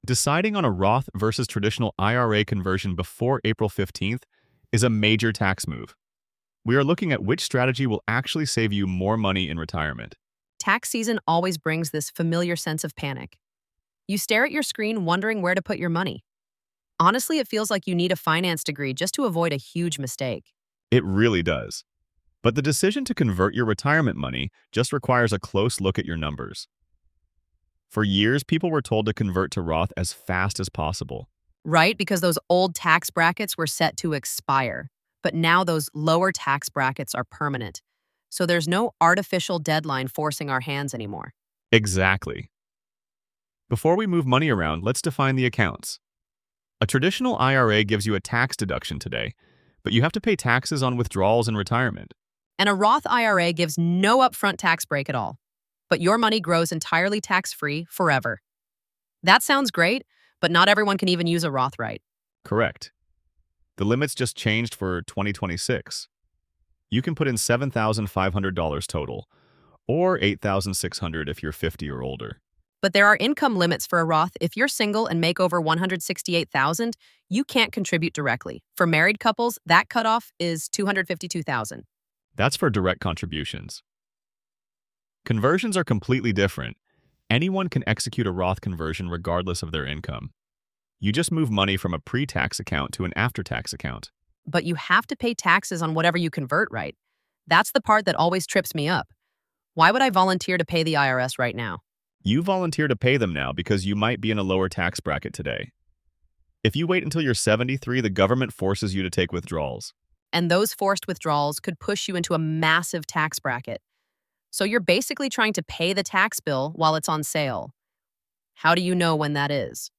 AI-generated audio · Voices by ElevenLabs